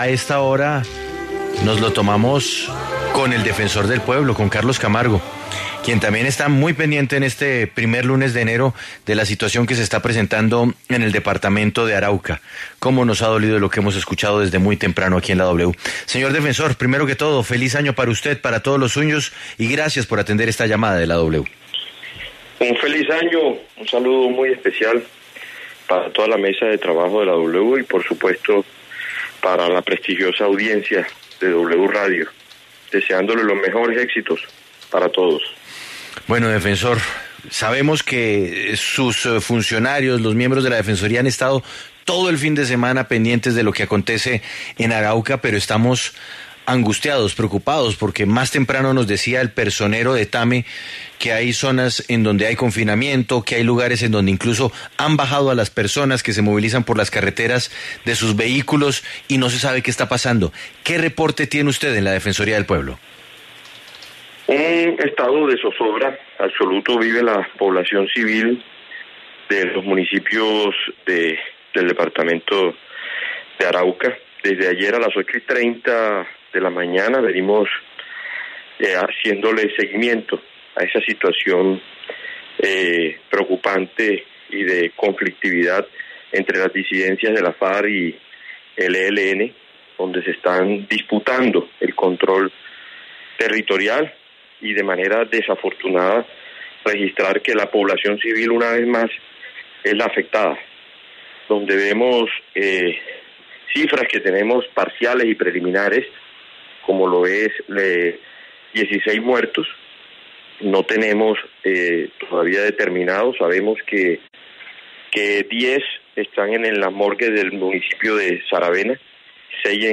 En entrevista con La W, el Defensor del Pueblo, Carlos Camargo, habló sobre la crisis que se vive en varios municipios de Arauca, la cual deja varios muertos y población civil confinada y desplazada.